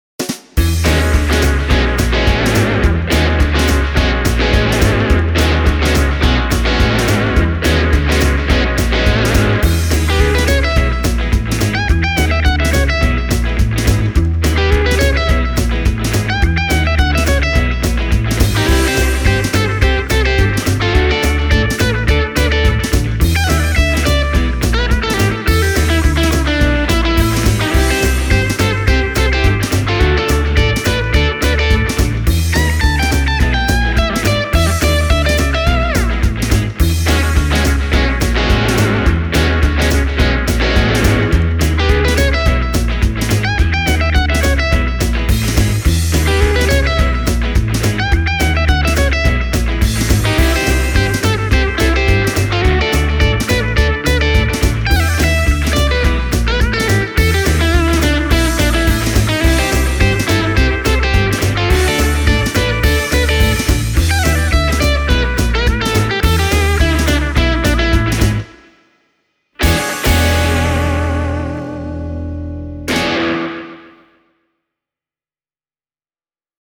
Demobiisin kitararaidat on äänitetty T-Rex Replicator -nauhakaiun läpi: